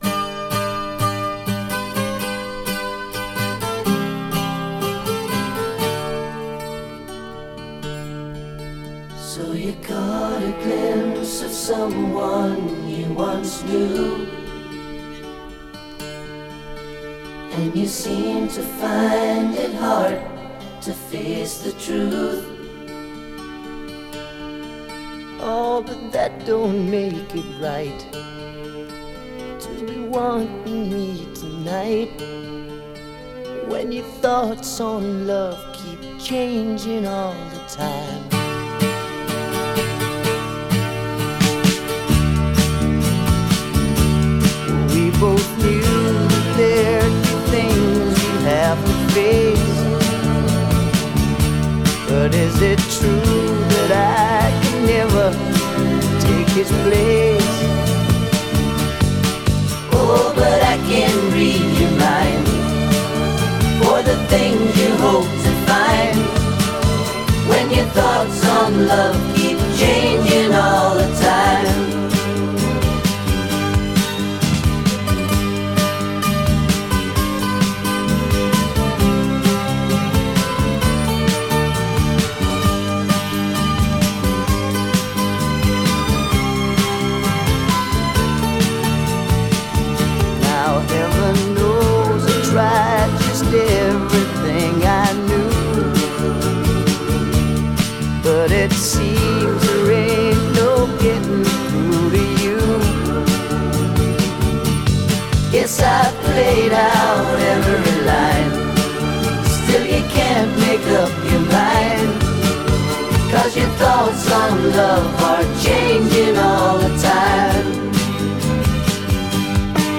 Genre: Pop Rock.